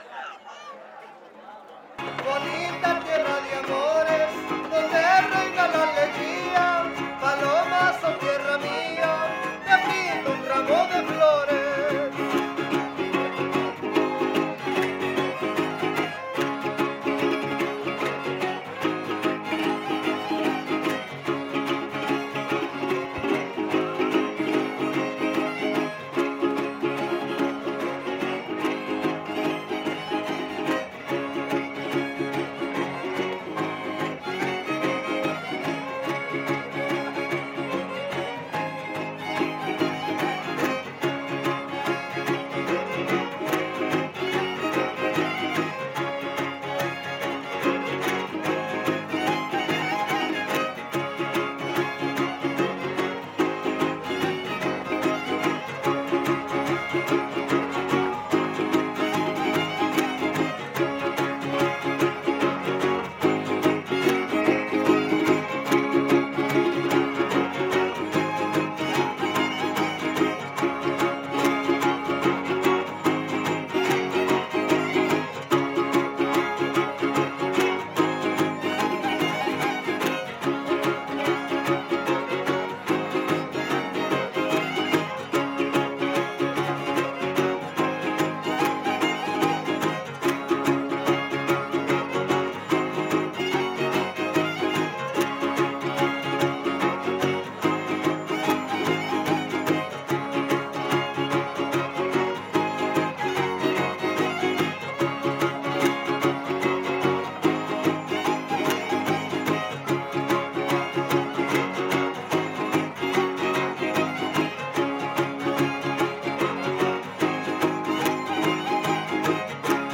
07 Secuencia arribeña